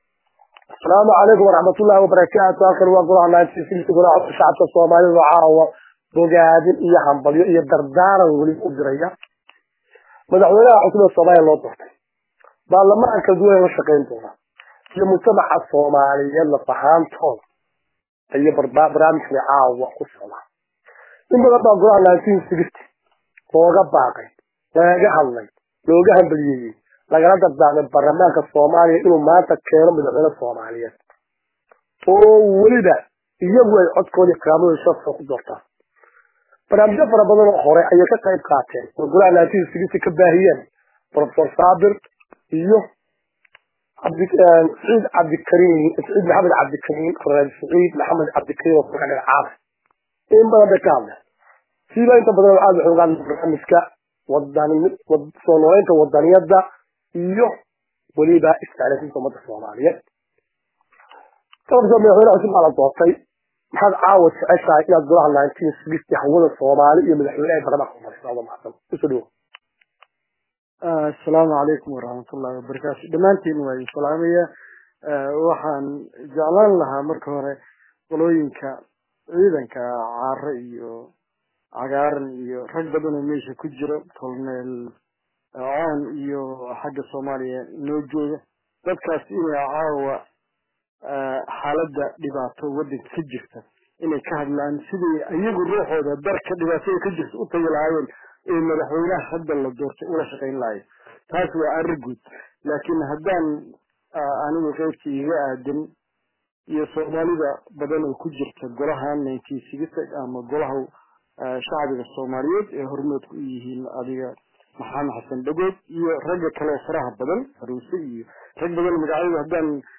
Waraysigan waxay si balaaran ugaga hadleen aqooyahanadu .